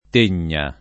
[ t % n’n’a ]